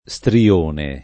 istrione [ i S tri- 1 ne ] s. m.